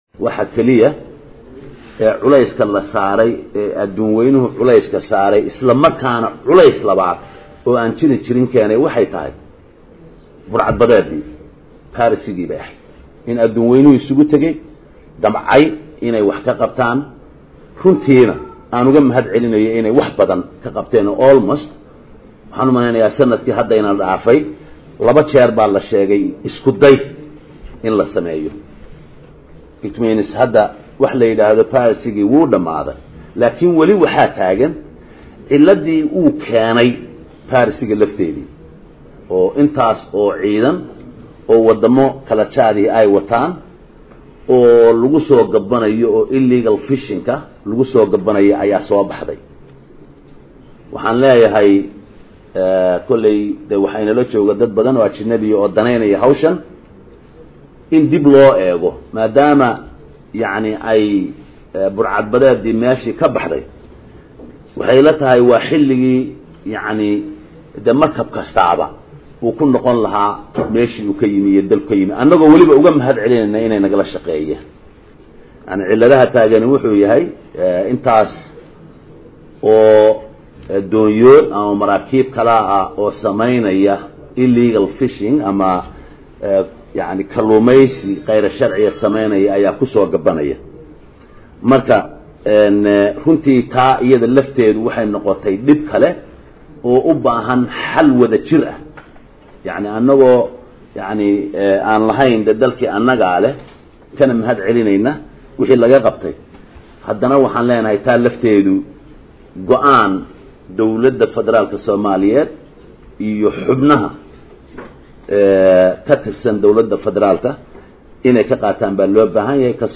C/xakiin C/laahi Xaaji Cumar Camay madaxweyne xigeenka dowlada Puntland ayaa mar uu ka hadlayay maraakiibta kusugan xeebaha Puntland ee samaynaya kaluumaysiga sharci daradaa waxaa uu sheegey in la gaarey xiligii maraakiibtaan ku laaban lahaayeen dalalkooda .